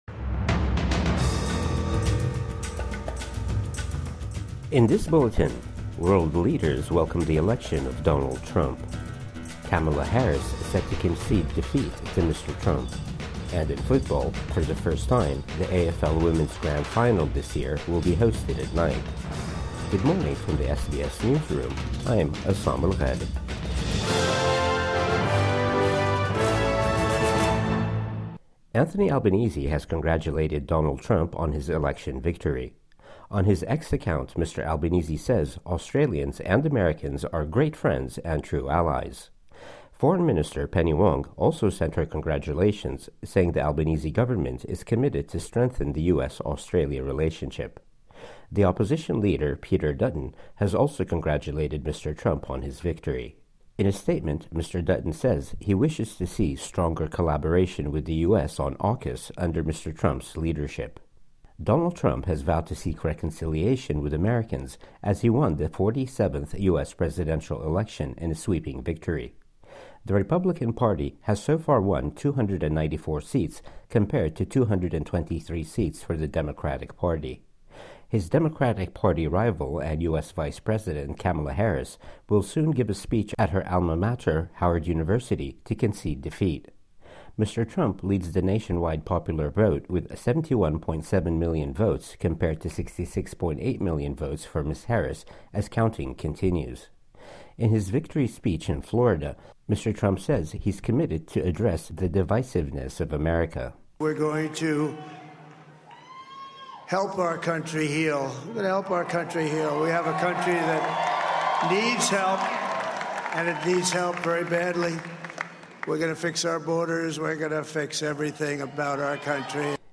Morning News Bulletin 7 November 2024